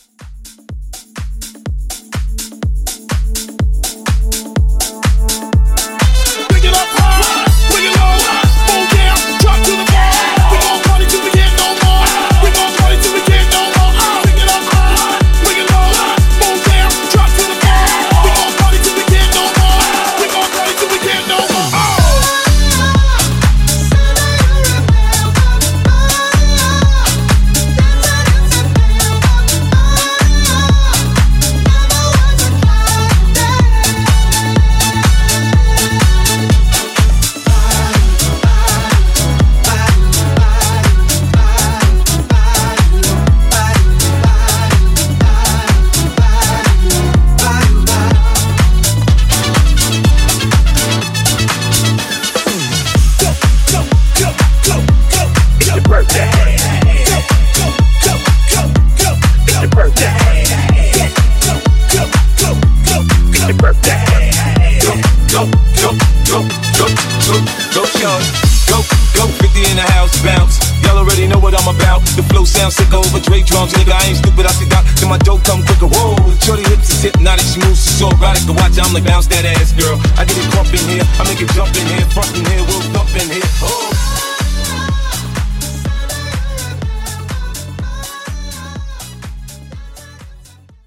BPM: 124 Time